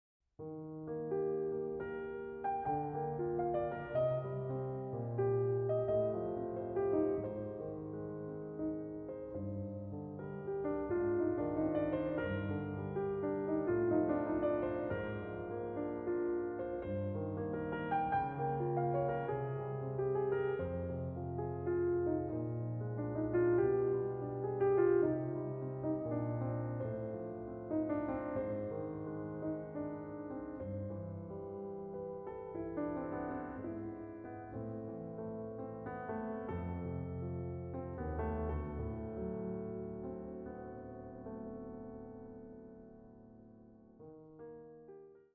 これらの「聴きやすい」曲にはクライマックスや終着点が無く、宙に浮くように美しい叙情性だけがいつまでも残っていきます。